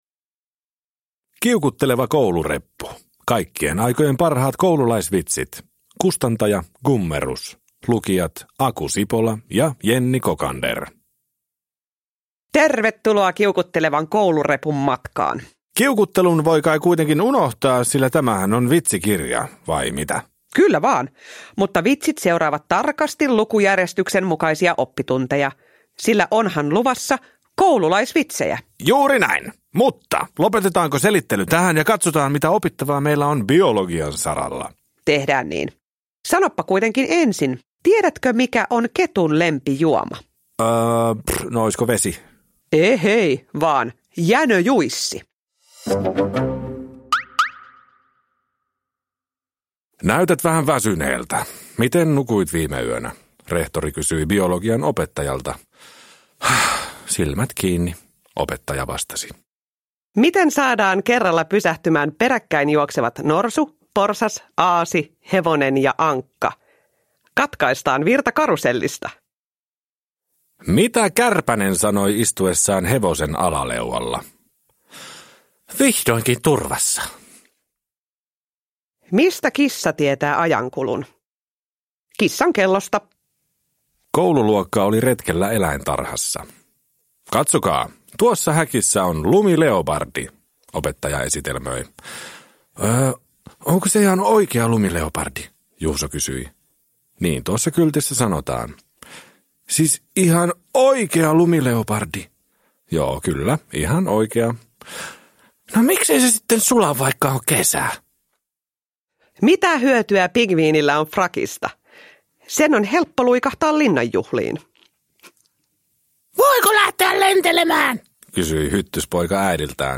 Kiukutteleva koulureppu – Ljudbok